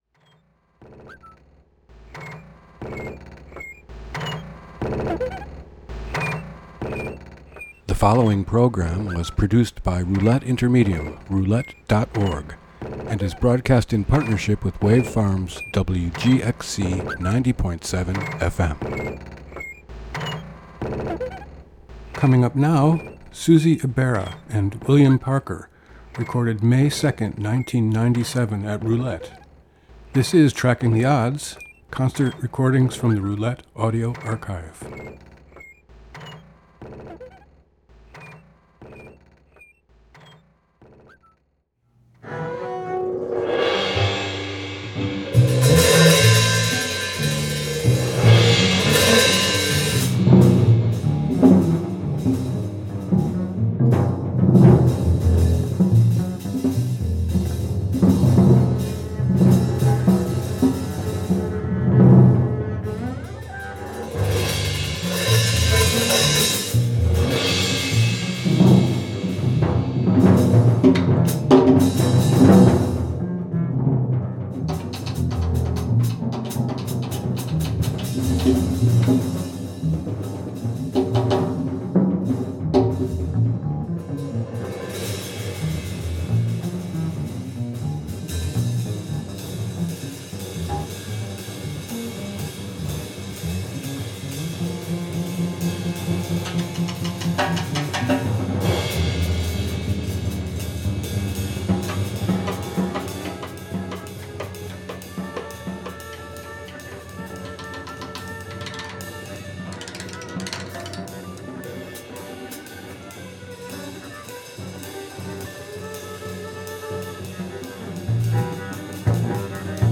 Recorded in concert on May 2, 1997
drums and percussion